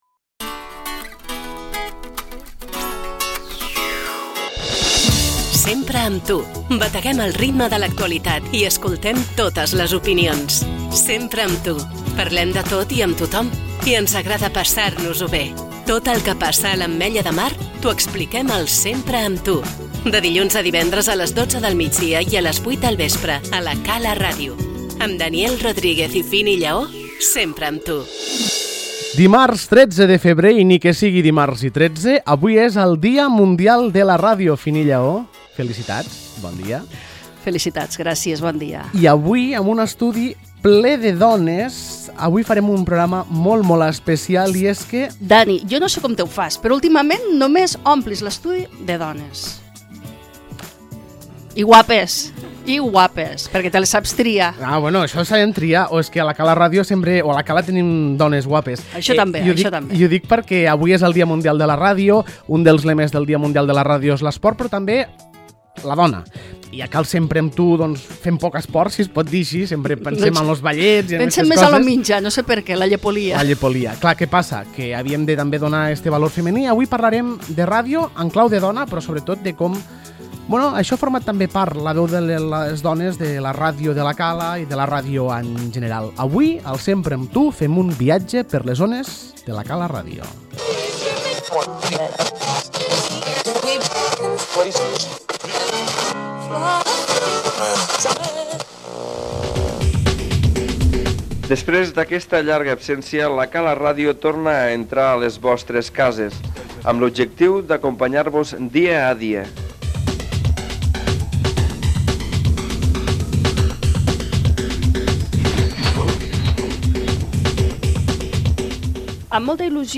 El Dia Mundial de la Ràdio hem reunit antigues treballadores, col·laboradores i joves periodistes per parlar del passat, el present i el futur de la ràdio local, i en especial de La Cala Ràdio.